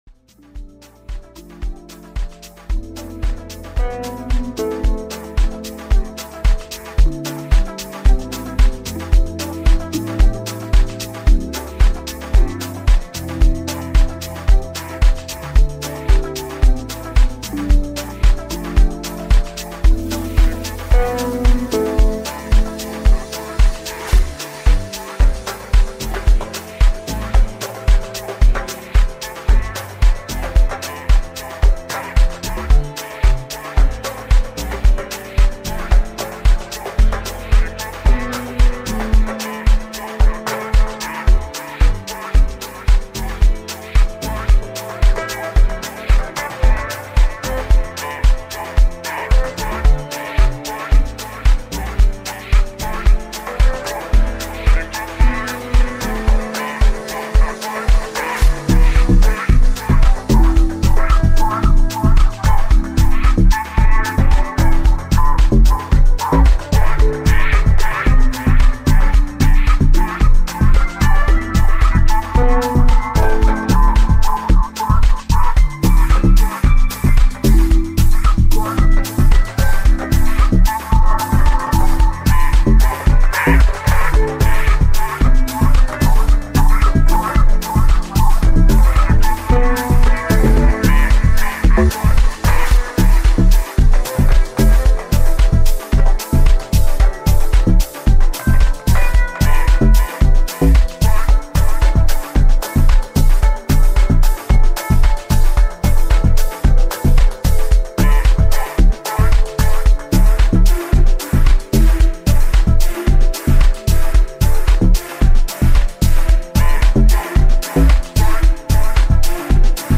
pulsating beats and seamless transitions
mixtape
electrifying energy